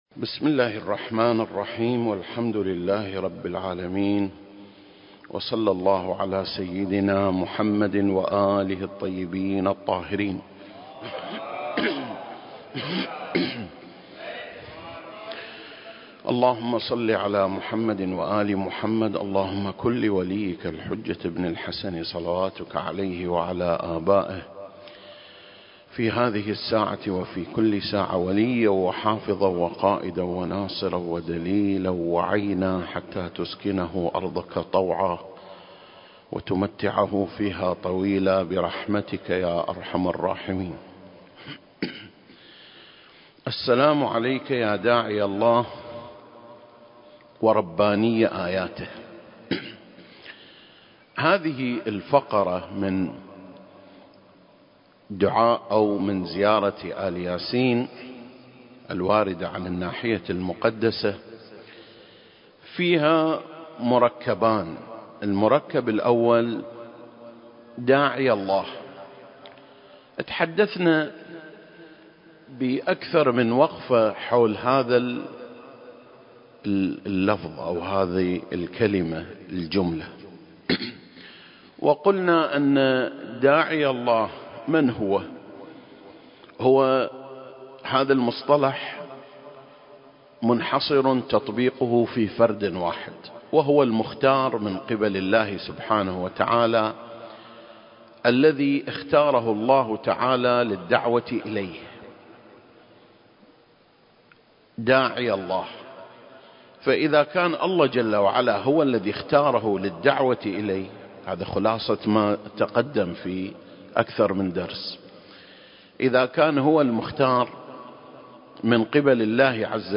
سلسلة: شرح زيارة آل ياسين (31) - رباني آياته (1) المكان: مسجد مقامس - الكويت التاريخ: 2021